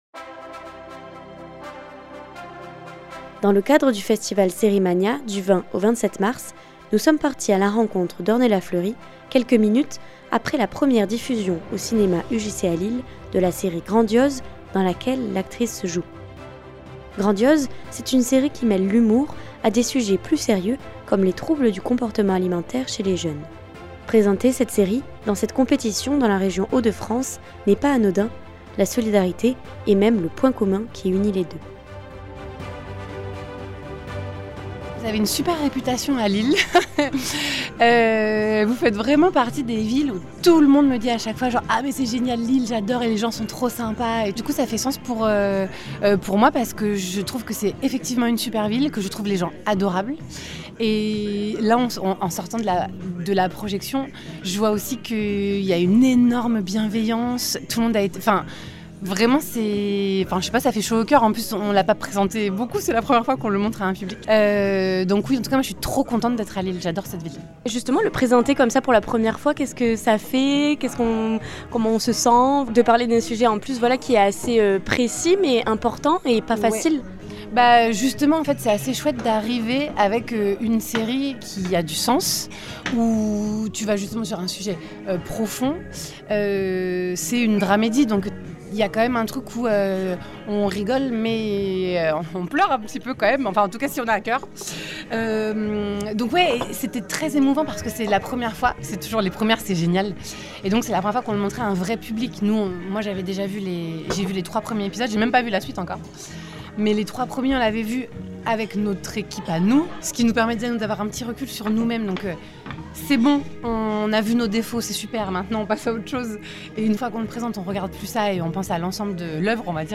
3. REPORTAGES
Rencontre avec Ornella Fleury actrice au sein du tournage.